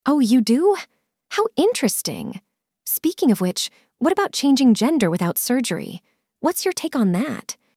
話題性の高いニュースTopic「性別の変更と平等」に関する会話テキストを元に、アメリカ英語の自然な口語を学びます。
友人どうしが時事問題について話し合っています。